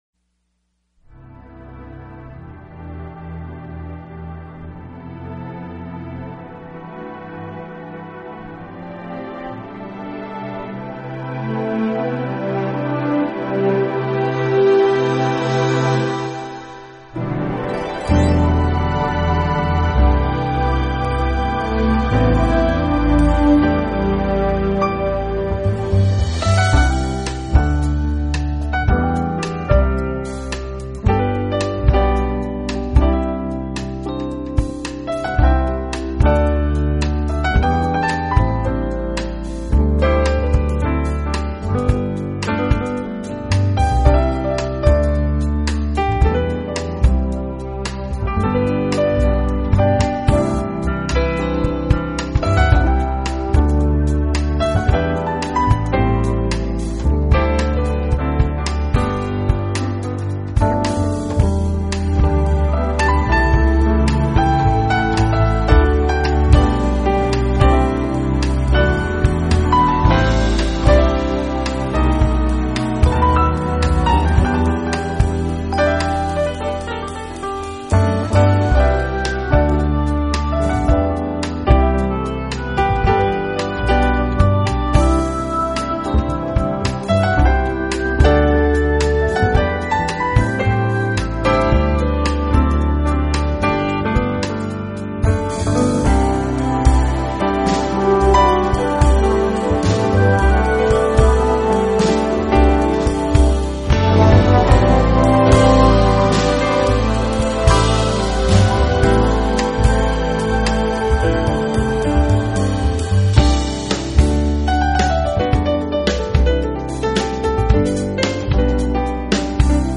音乐风格：Smooth Jazz